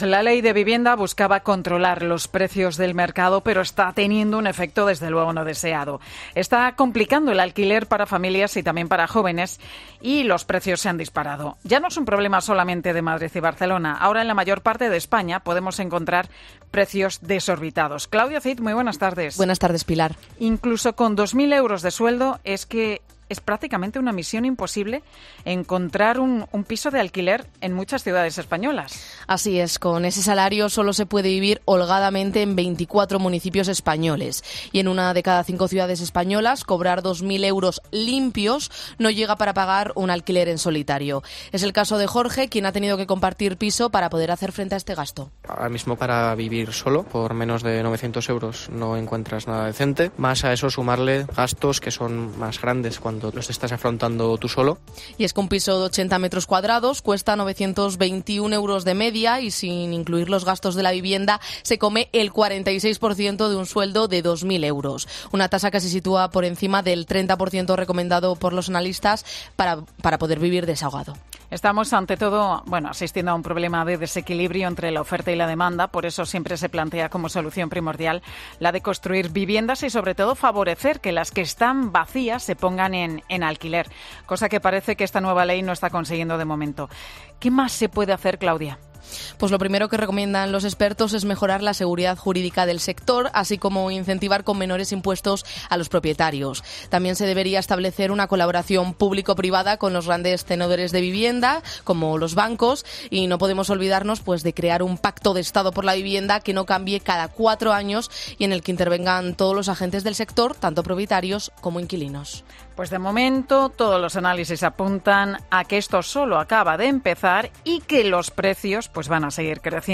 "Por menos de 900 euros no encuentras nada decente" para vivir, cuenta a COPE un joven que señala, además, que "hay que sumarle más gastos que son más grandes cuando estás solo".